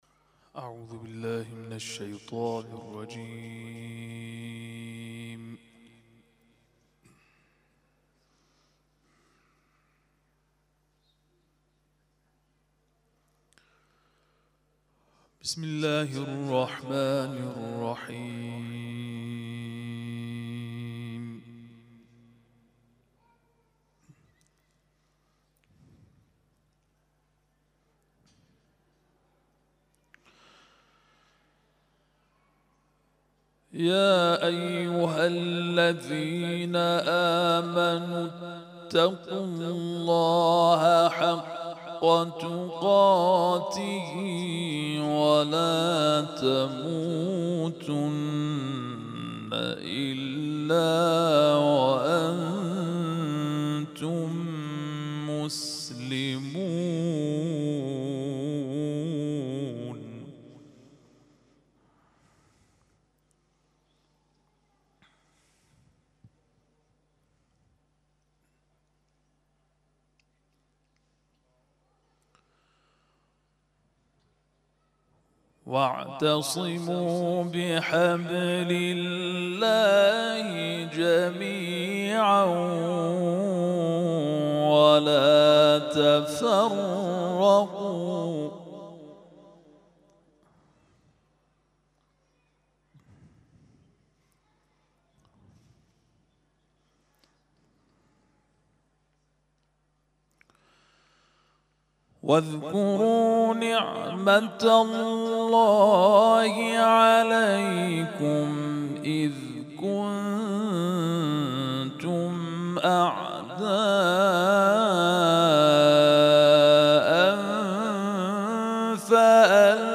تلاوت ظهر - سوره بقره آیات ( ۱۹۶ الی ۱۹۹) Download